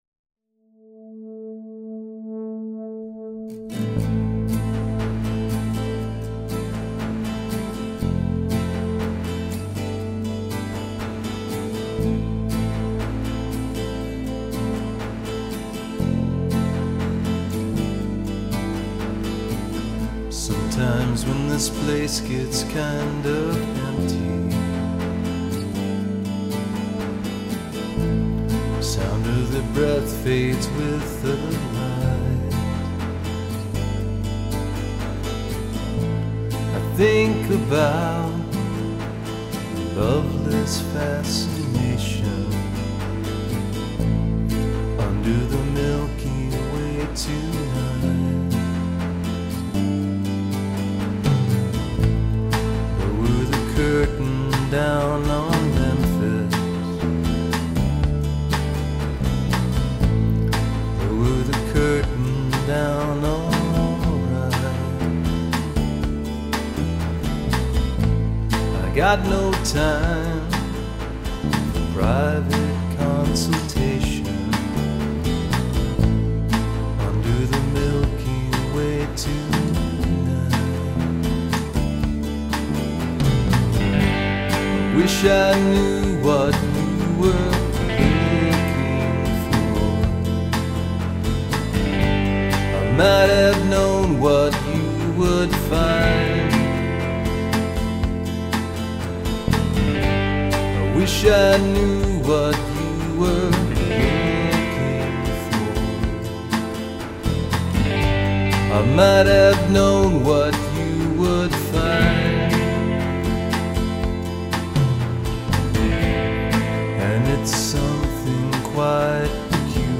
Partially as a test of my new studio gear
not-completely-acoustic version